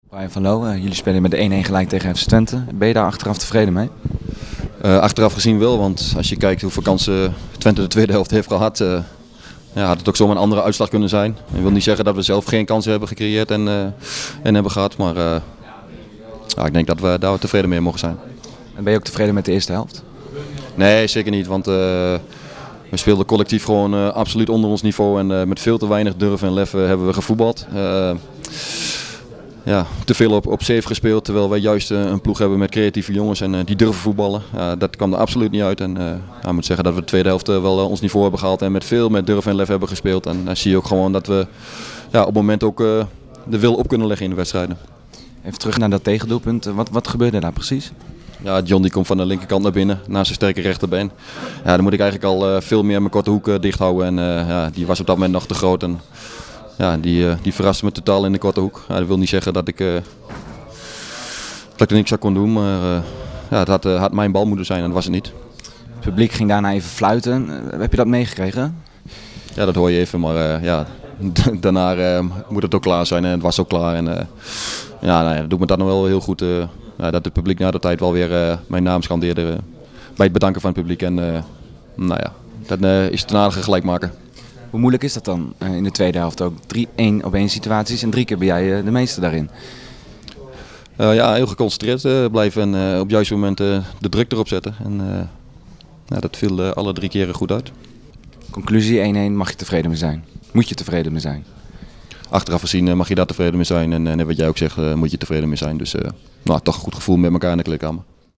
Luister hier naar de reactie van keeper Brian van Loo